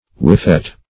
Whiffet \Whif"fet\, n.